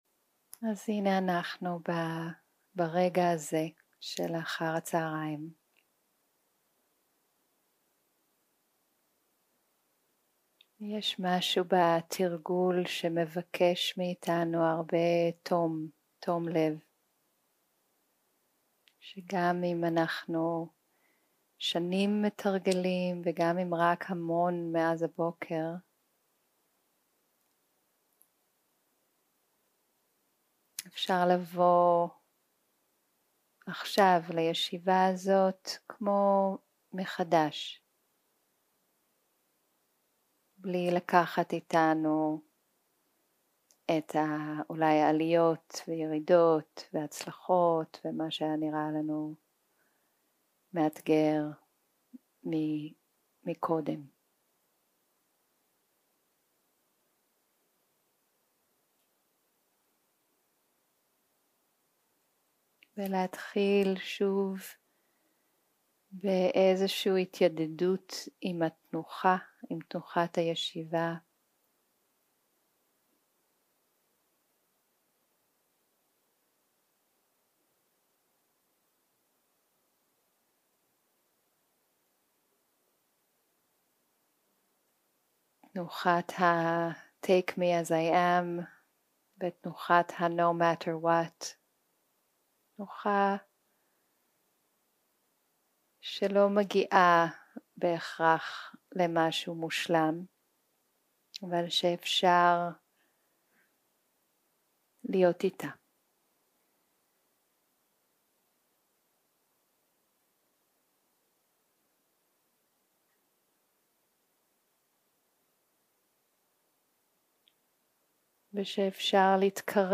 יום 2 - הקלטה 3 - צהרים - מדיטציה מונחית - תשומת לב לגוף ונשימה
יום 2 - הקלטה 3 - צהרים - מדיטציה מונחית - תשומת לב לגוף ונשימה 0:00 0:00 סוג ההקלטה: Dharma type: Guided meditation שפת ההקלטה: Dharma talk language: Hebrew